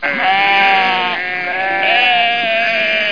Amiga 8-bit Sampled Voice
sheep.mp3